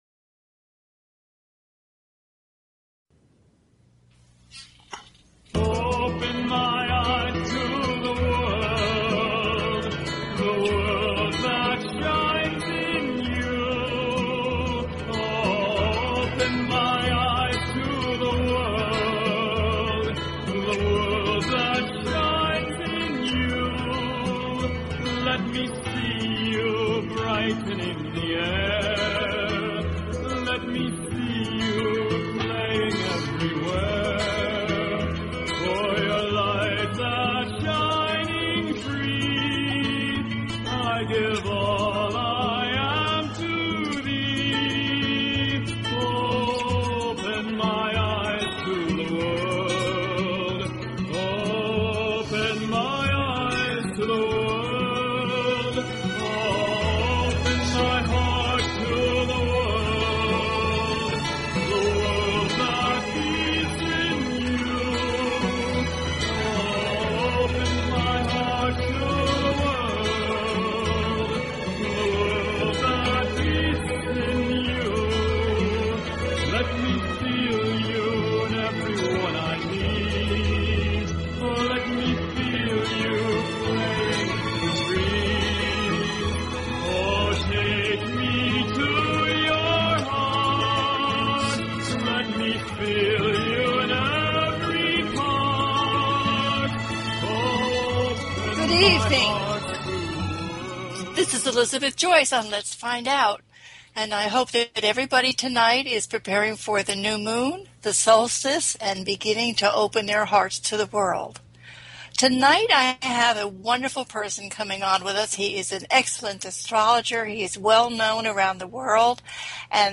Talk Show Episode
THIS IS A CALL IN SHOW, so please, if you want to know about your pesonal summer changes, get your questions ready and give us a call.